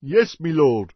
PeasantYes2.mp3